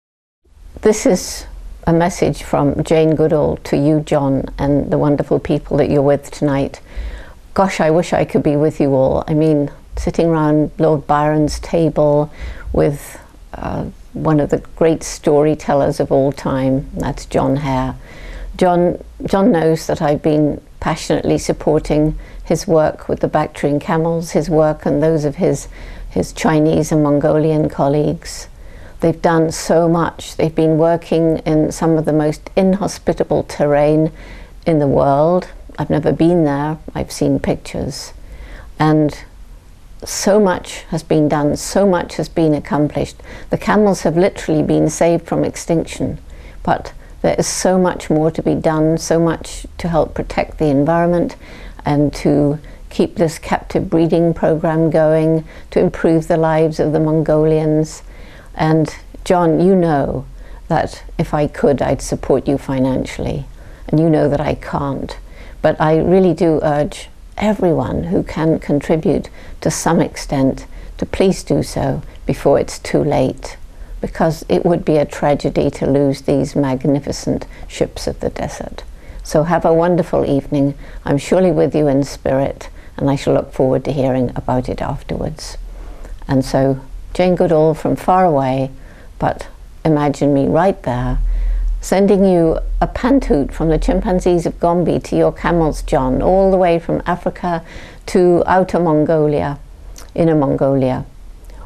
Mensaje de voz del Excmo. Patrón vitalicio: